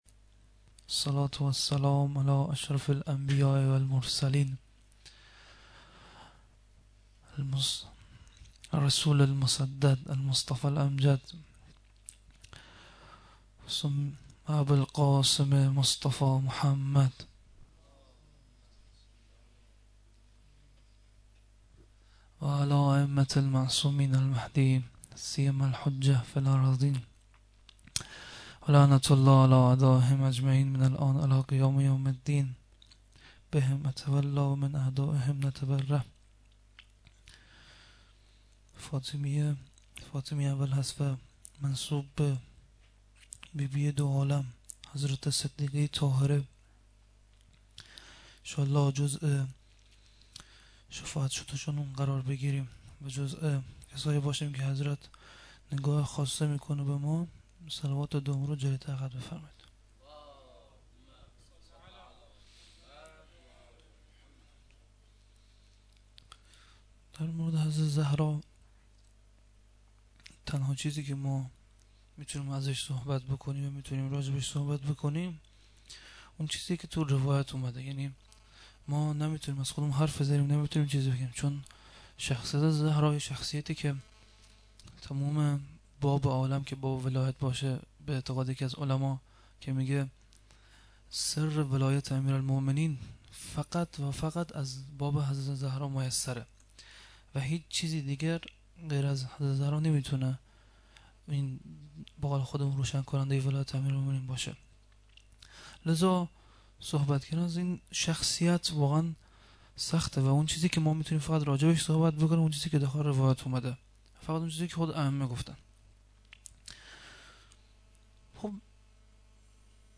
fatemieh-aval-92-shab1-sokhanrani-2.mp3